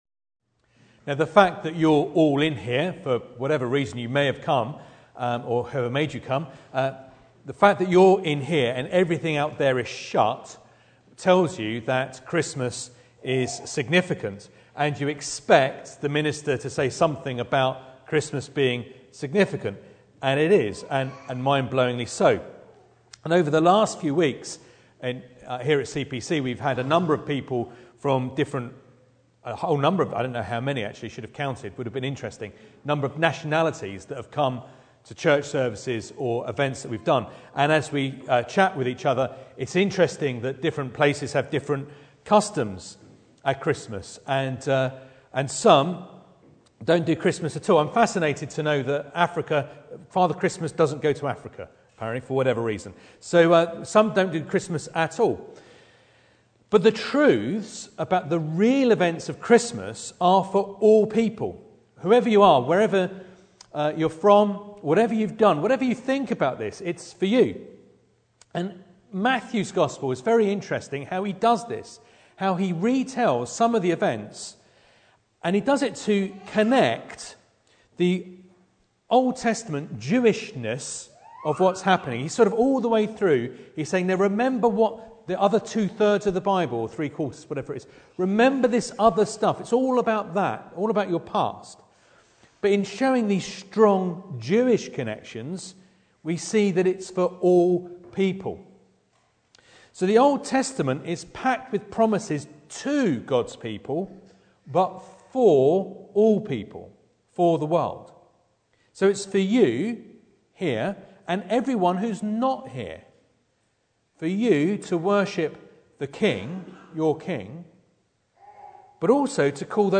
Service Type: Midweek